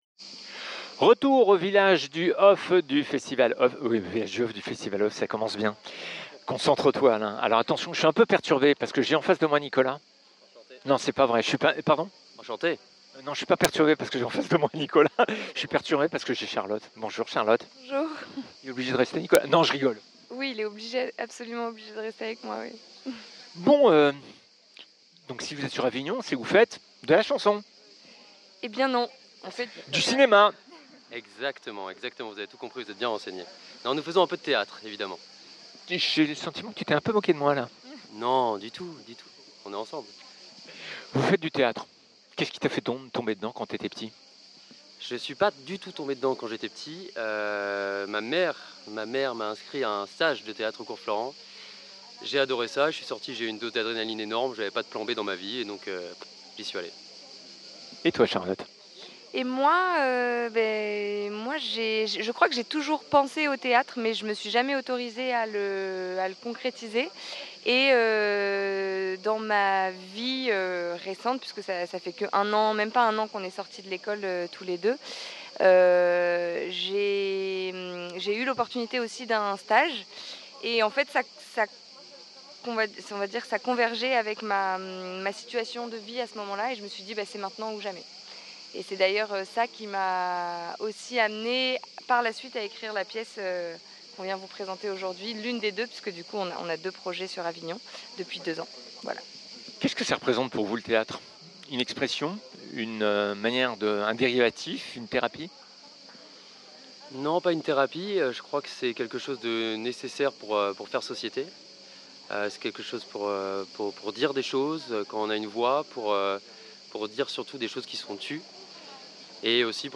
interprètes du spectacle Démons sur le Festival Off Avignon 25 en partenariat avec Chacomdif